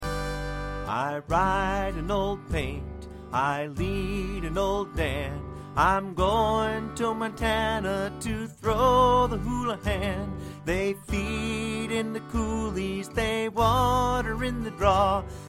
Traditional Song Lyrics and Sound Clip